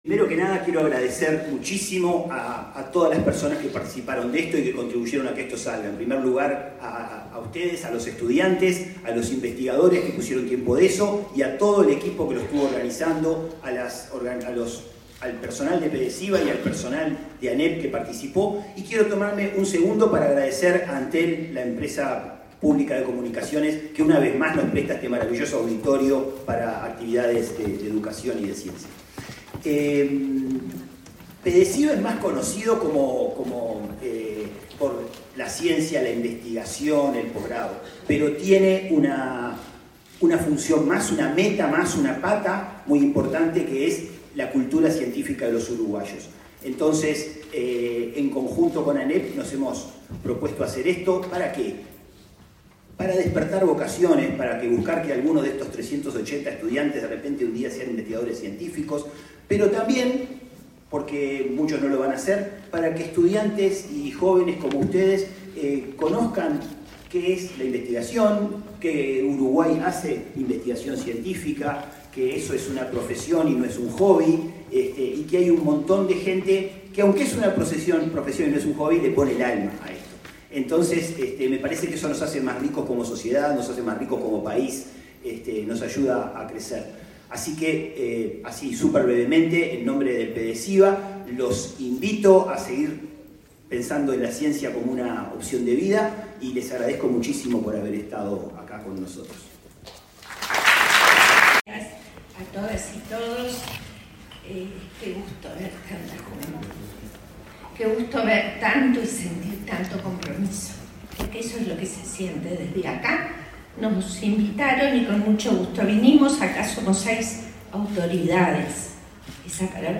Palabras de autoridades en el cierre del programa Ciencia Joven 2025